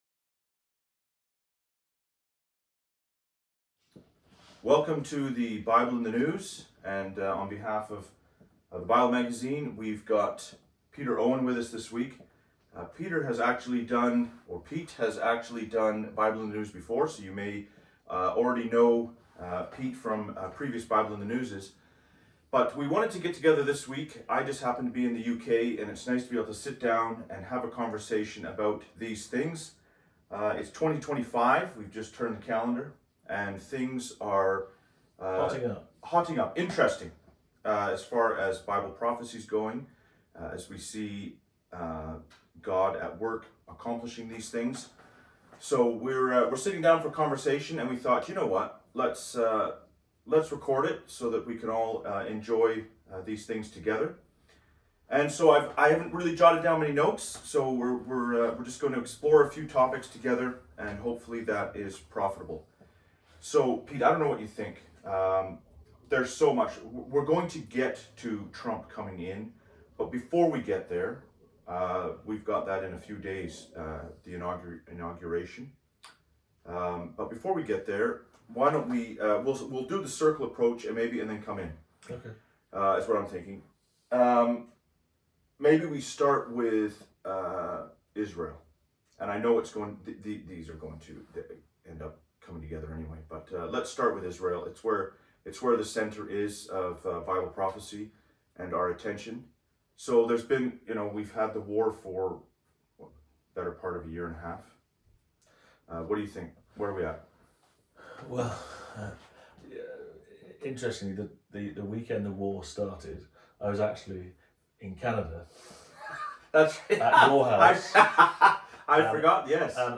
A Discussion: Looking Forward to Trump's Inauguration and the Year Ahead What Will Happen Next?